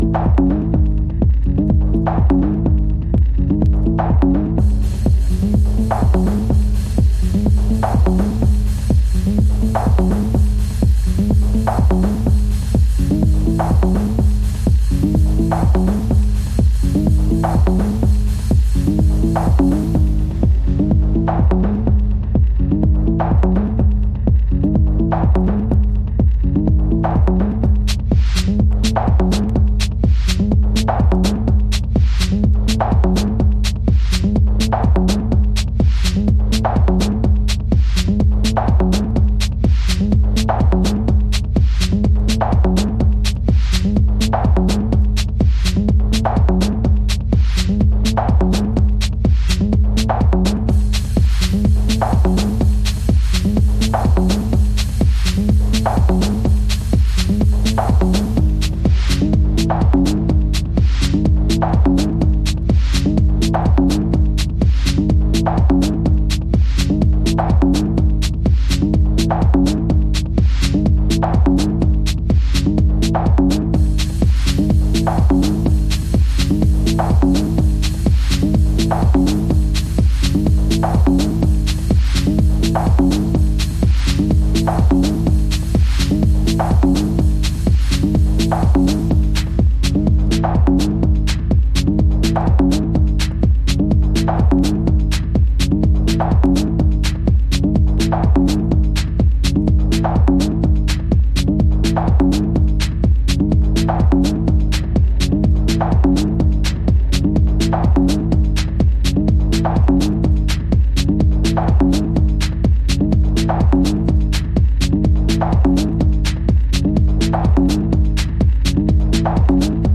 House / Techno
硬質なビートで疾走、ひたすら地を這うようなテクノトラックス。